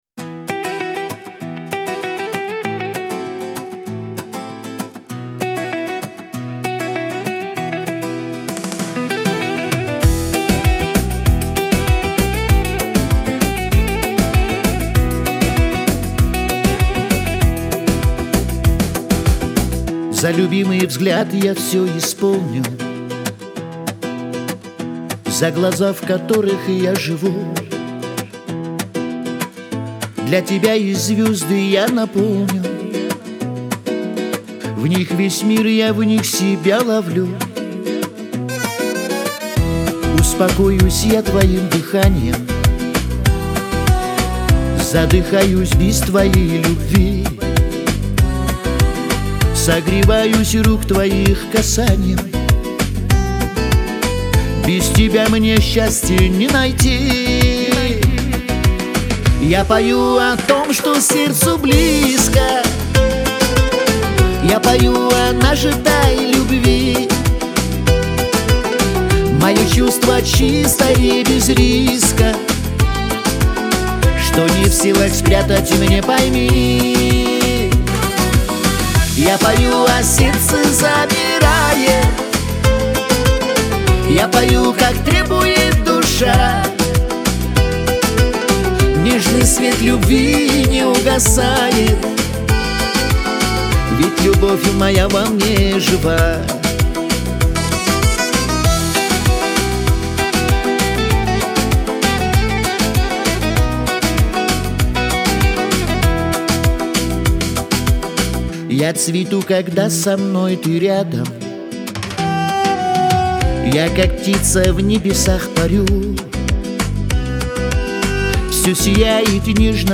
Лирика , Шансон